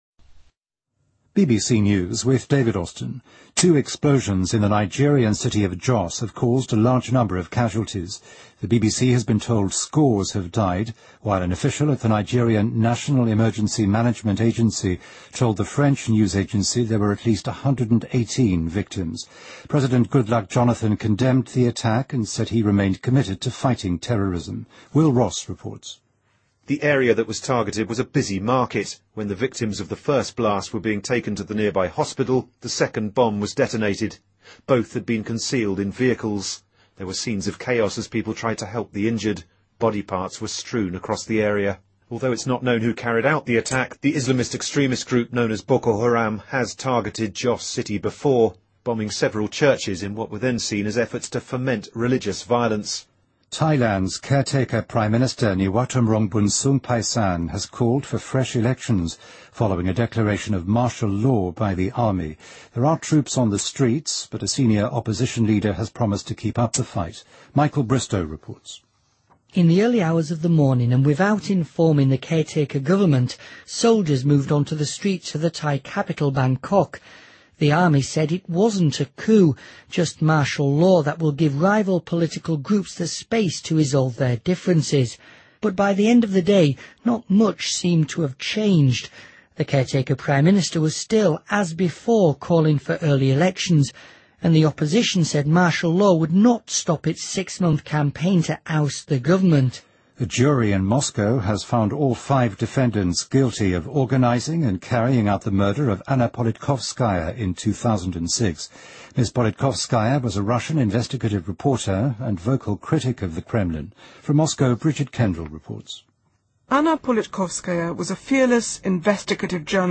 BBC news,白宫称中情局不再使用疫苗项目作为间谍活动的掩护